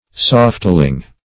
Search Result for " softling" : The Collaborative International Dictionary of English v.0.48: Softling \Soft"ling\, n. A soft, effeminate person; a voluptuary.